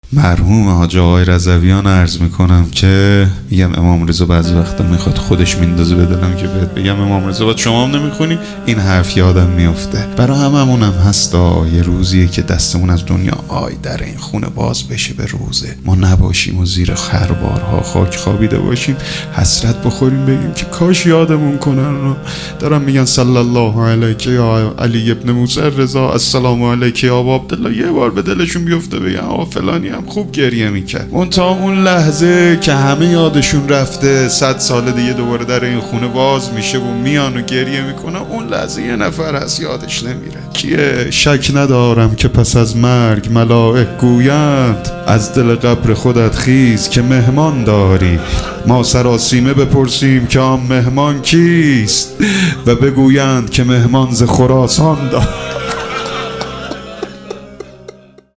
میلاد حضرت رقیه سلام الله علیها 1400 | هیئت ام ابیها قم